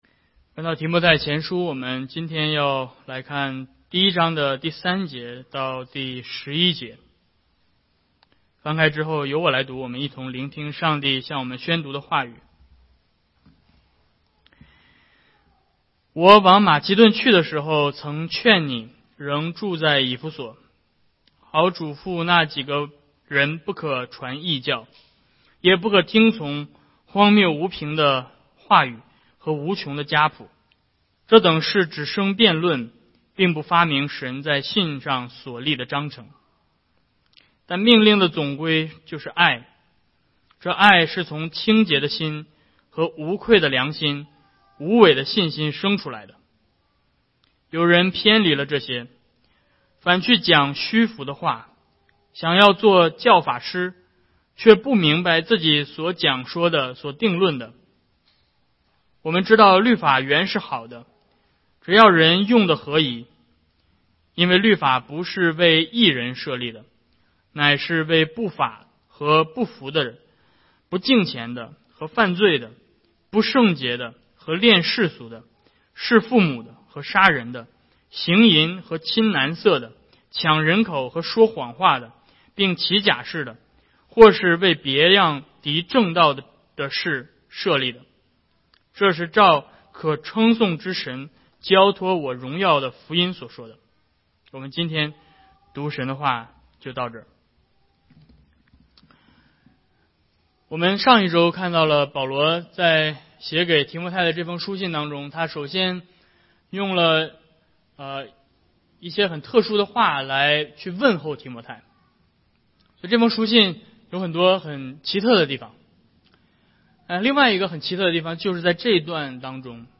教牧书信系列 Passage: 1 Timothy1:3-11 Service Type: 主日讲道 Download Files Notes « 比利时信条